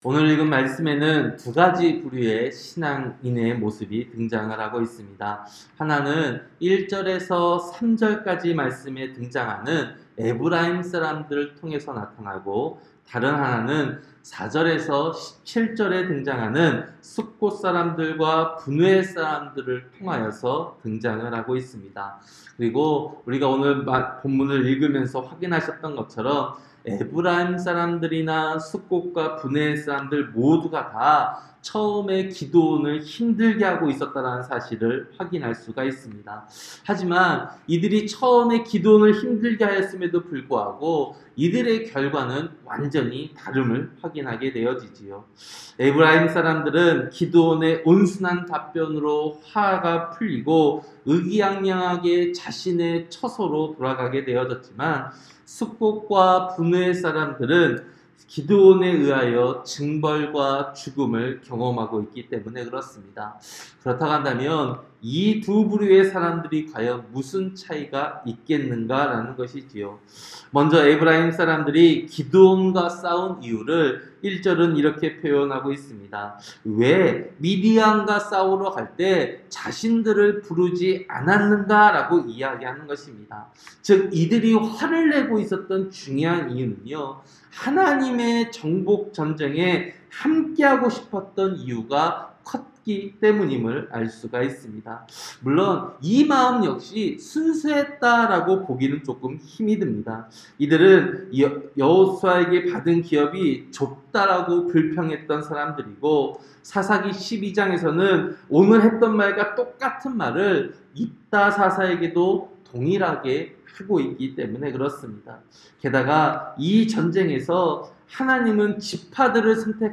새벽설교-사사기 8장